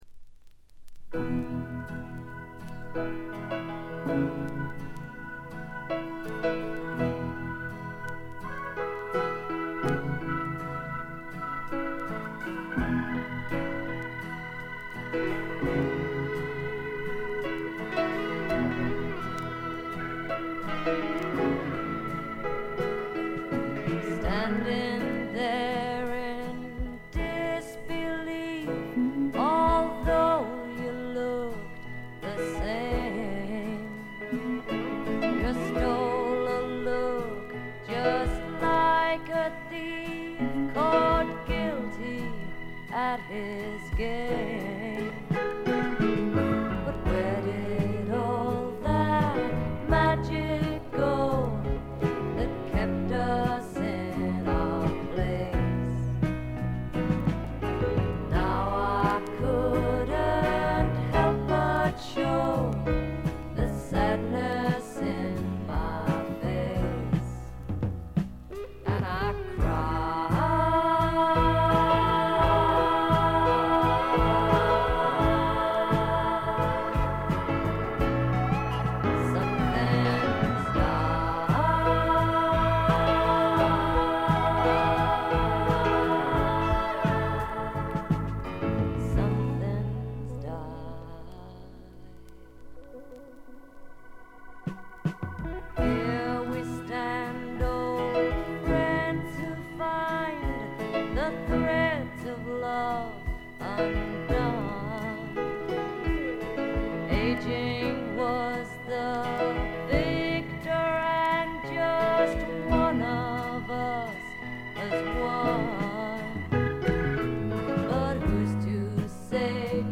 静音部での細かなチリプチ。
試聴曲は現品からの取り込み音源です。